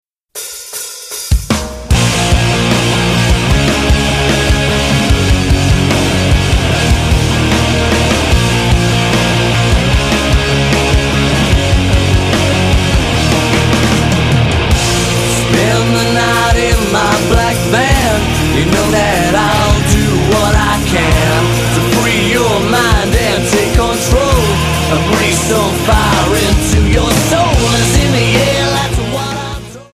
Alternative,Rock